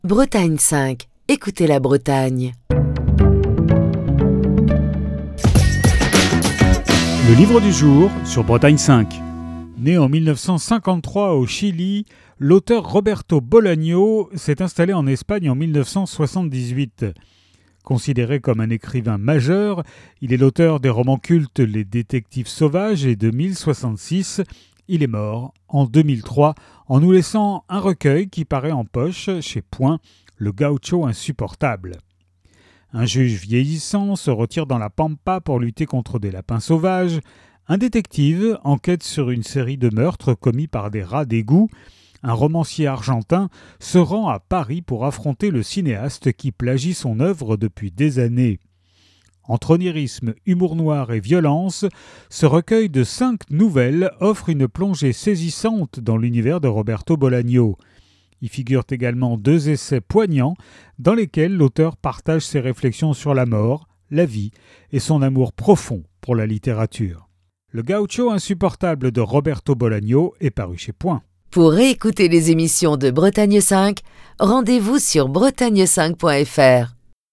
Chronique du 10 juillet 2025.